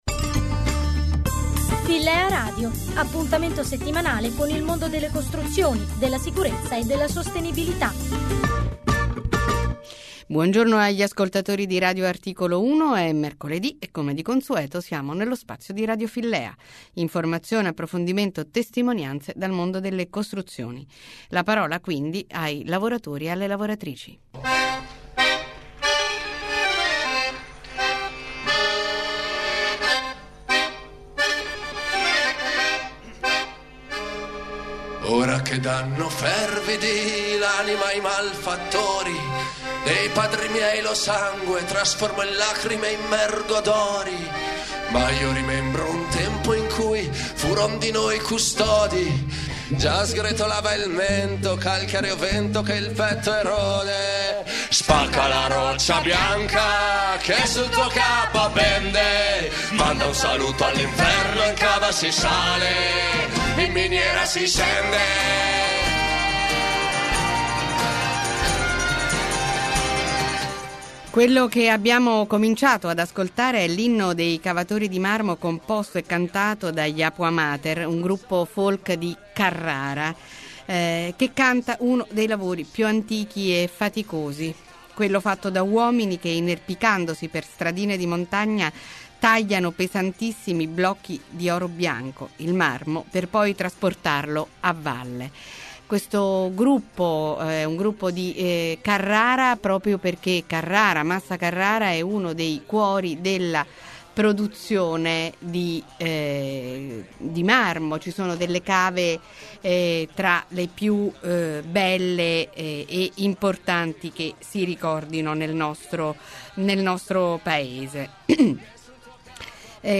RADIO FILLEA. LA PUNTATA DEL 9 FEBBRAIO 10.02.11 Bianco come il marmo, duro come la pietra: questo il titolo della puntata di RadioFillea, andata in onda su Radio Articolo1 mercoled� 9 febbraio.
due lavoratori